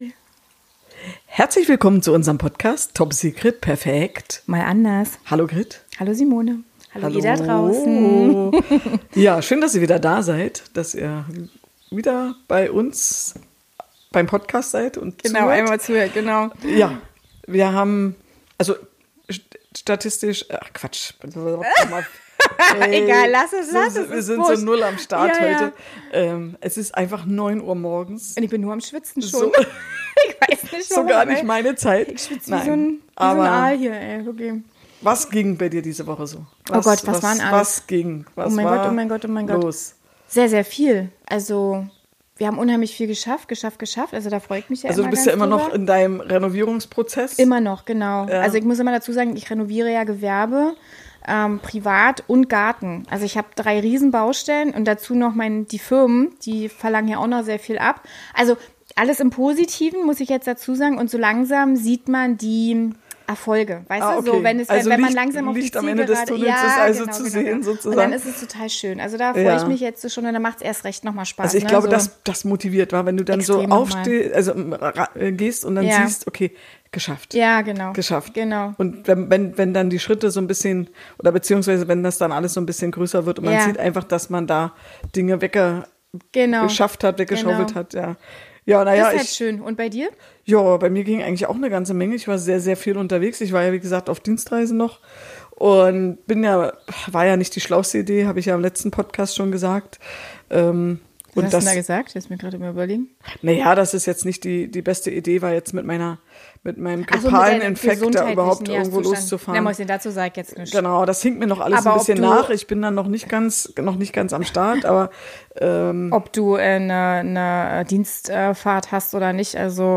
Alles was du anderen immer gepredigt hast, hast du selbst ignoriert und es kam zum Super Gau. Schwungvoll und humorvoll diskutiert, dann war es plötzlich halb so schlimm.